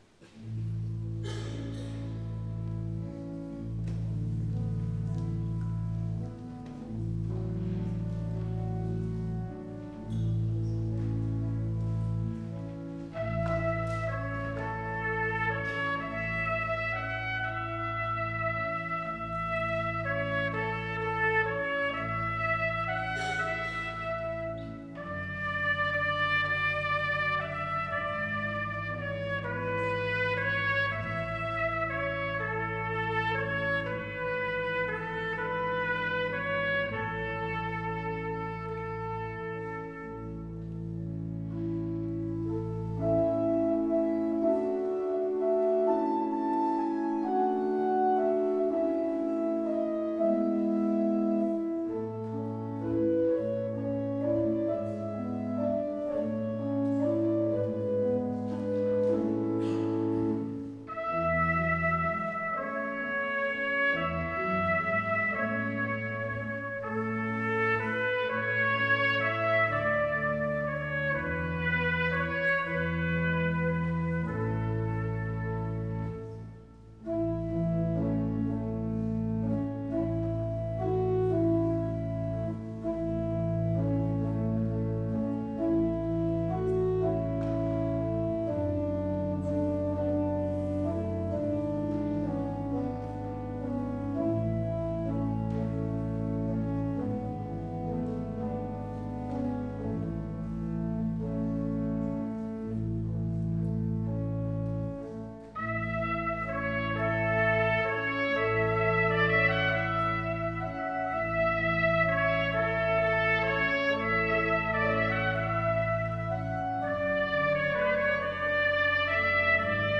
Livemitschnitt Radio Beo vom 25.12.2019